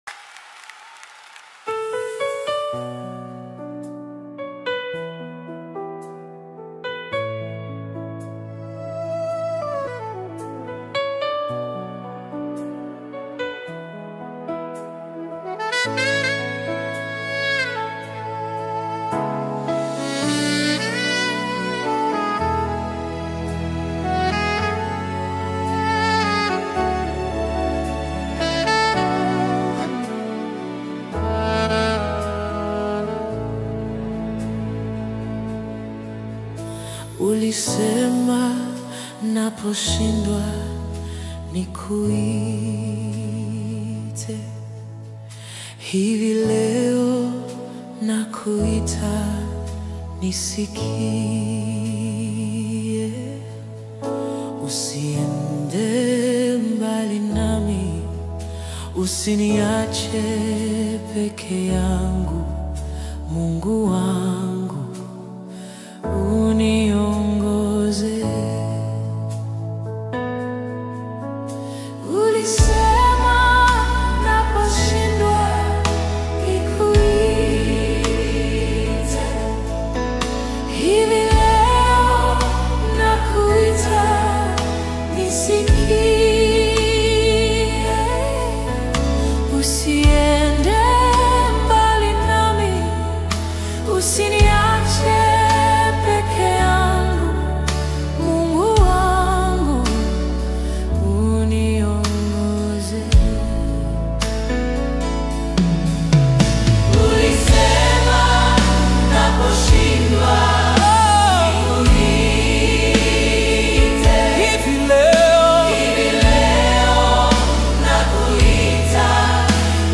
AUDIOGOSPEL